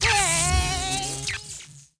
Cat Zap Sound Effect
Download a high-quality cat zap sound effect.
cat-zap-2.mp3